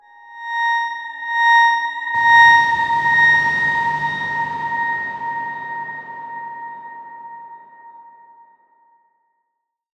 X_Darkswarm-A#5-mf.wav